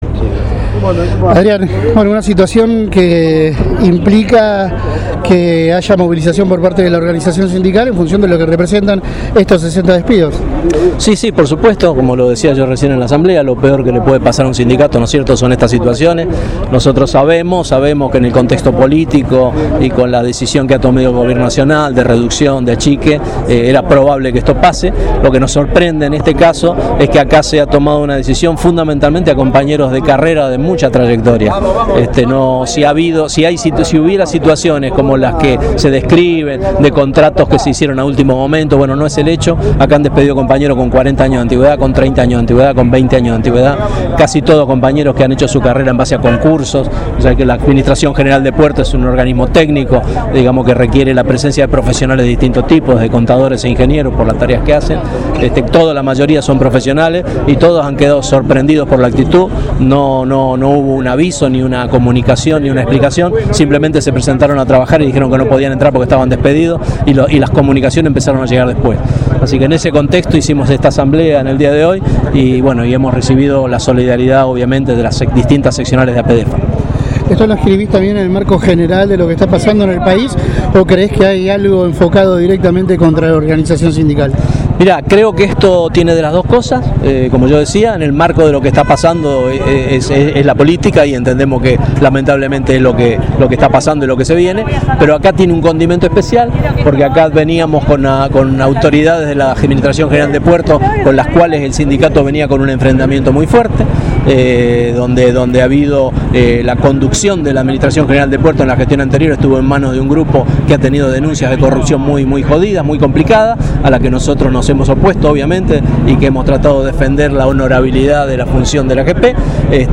A continuación compartimos la entrevista completa: